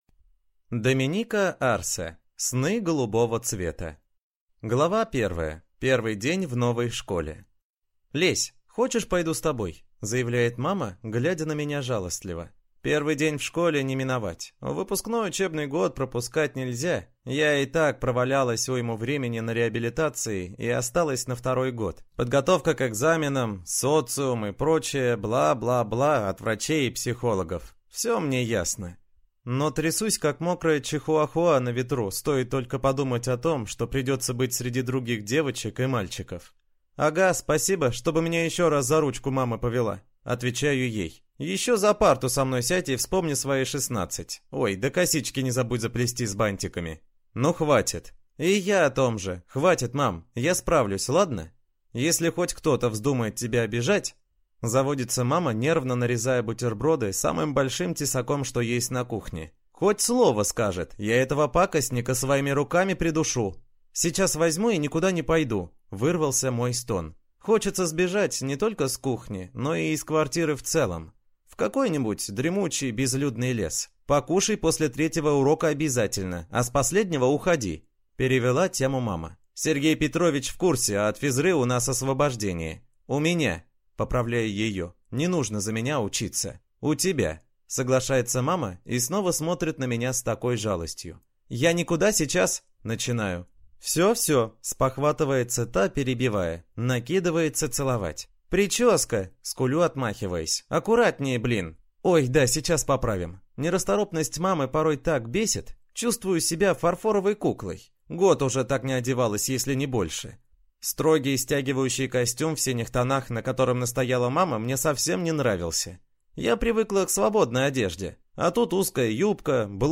Аудиокнига Сны голубого цвета | Библиотека аудиокниг